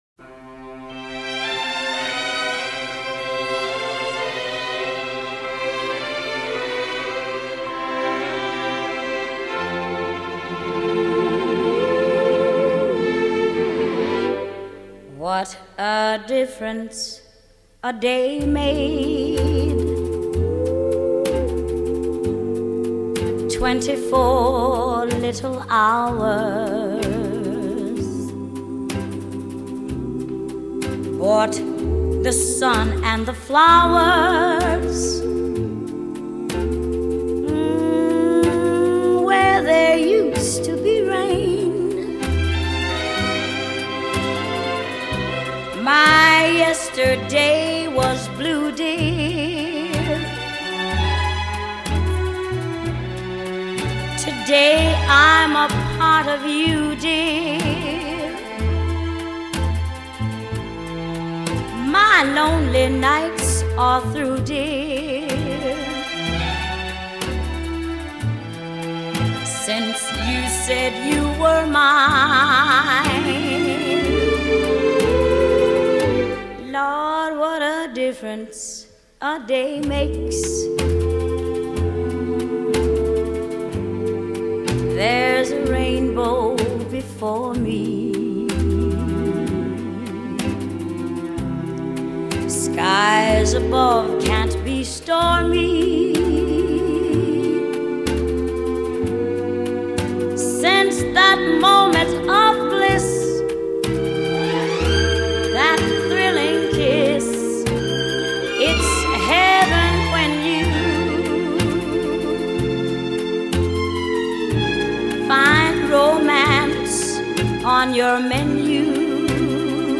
清晰的唱腔咬字以及完美的乐句表达